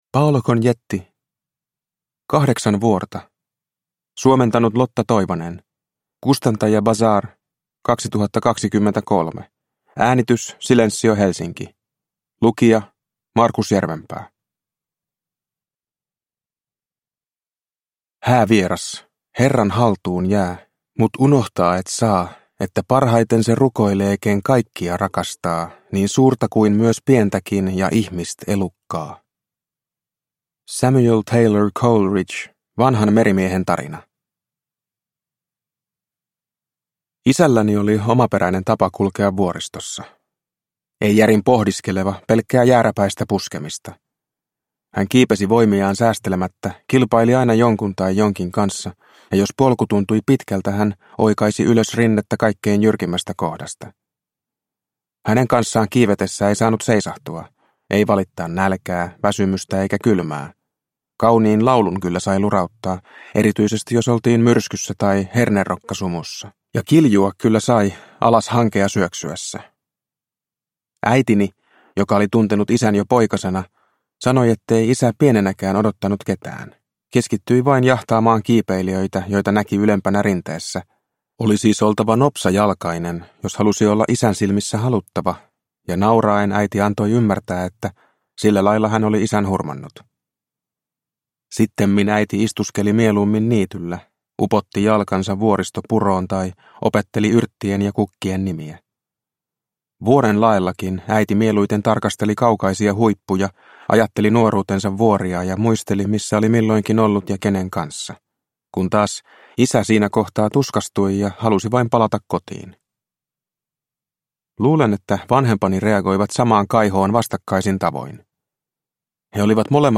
Kahdeksan vuorta – Ljudbok – Laddas ner